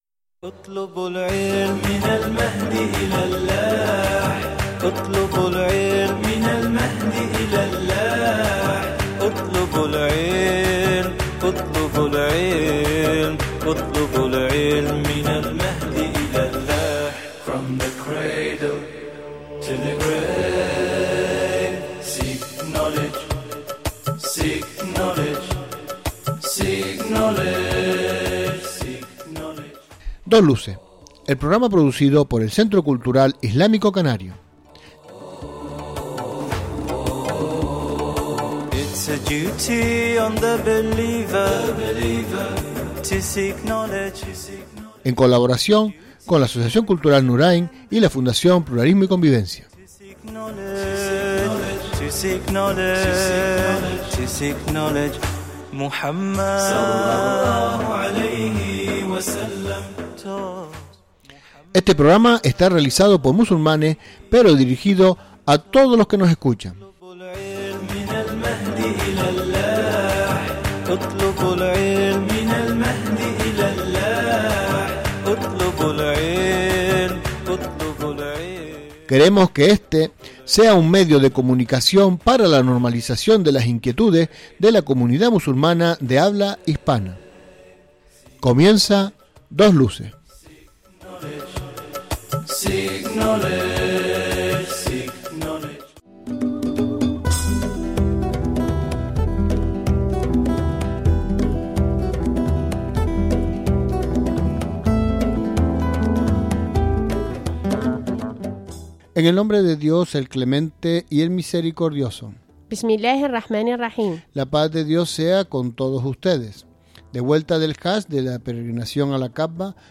El equipo de Radio Dos Luces ha salido a la peregrinación a Mekka (Hajj). Durante el tiempo que permanecermos fuera en vez de ponerles nuestro programa habitual les ondremos programas de con selecciones de musica musulmana.